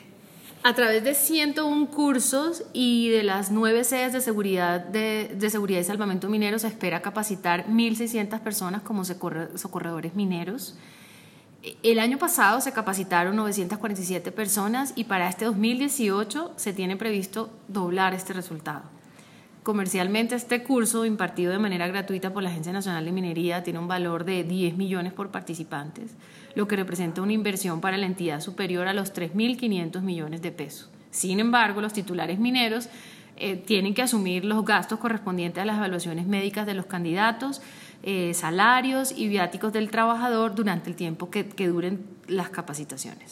Audio Presidenta Silvana Habib Daza